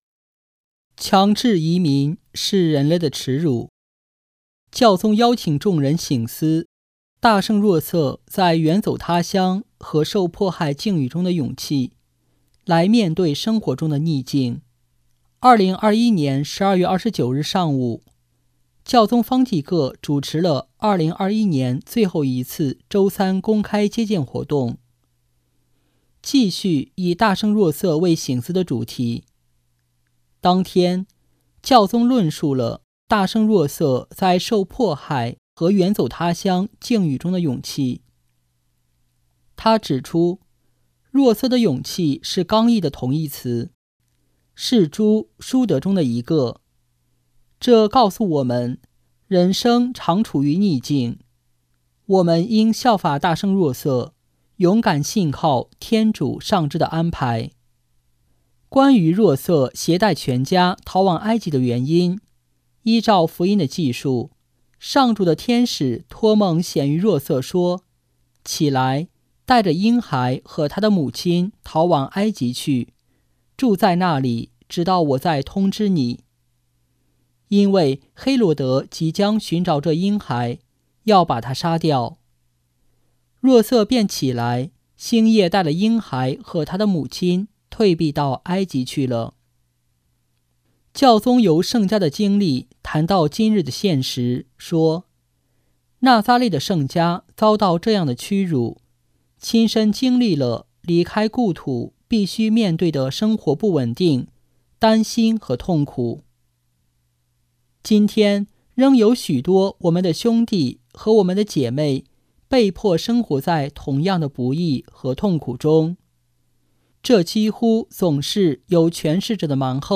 【听教宗讲道】|强制移民是人类的耻辱
1229日上午，教宗方济各主持了2021年最后一次周三公开接见活动，继续以大圣若瑟为省思的主题。